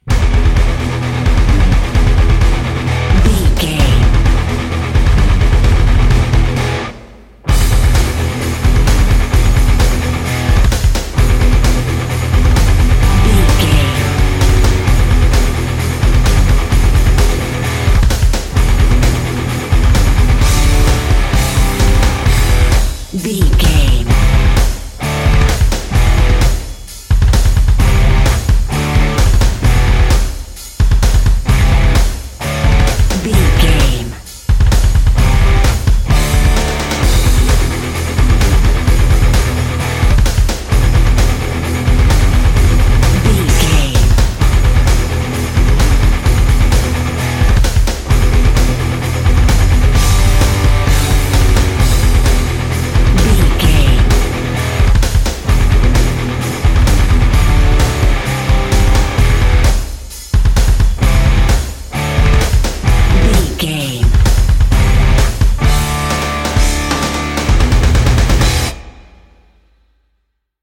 Aeolian/Minor
drums
electric guitar
bass guitar
hard rock
aggressive
energetic
intense
nu metal
alternative metal